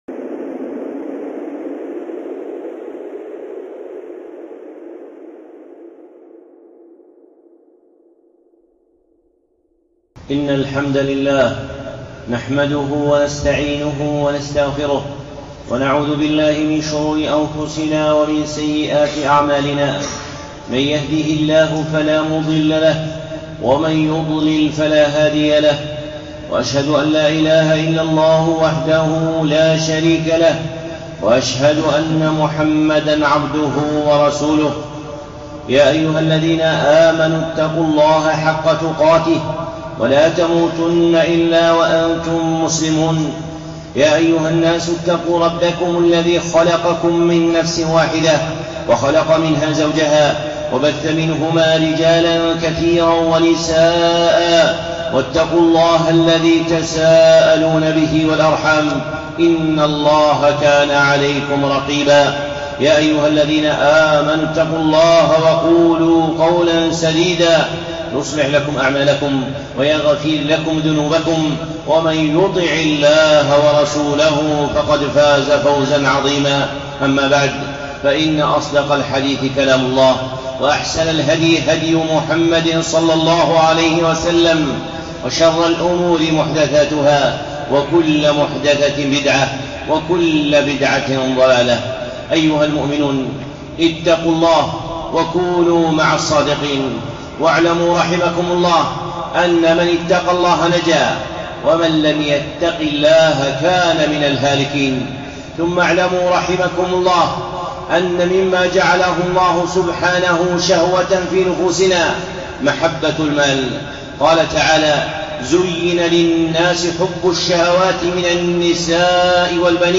خطبة (المخرج من فتنة المال)